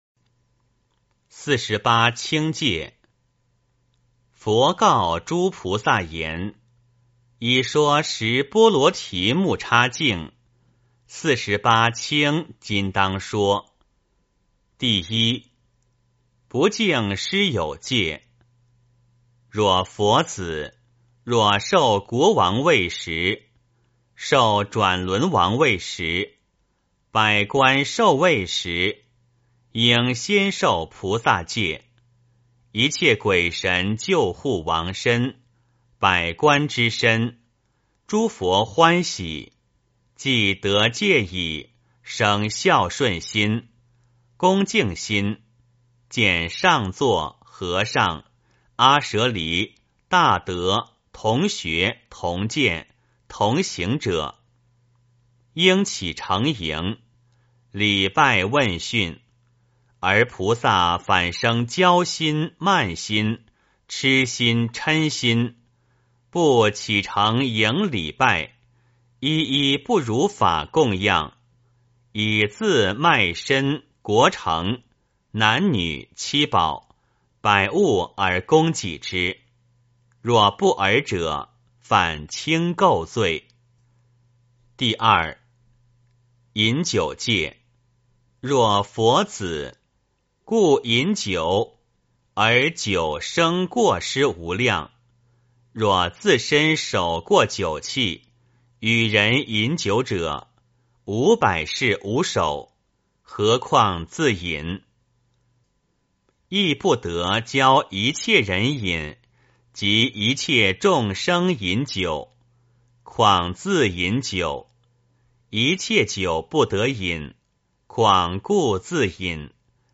梵网经-四十八轻戒 - 诵经 - 云佛论坛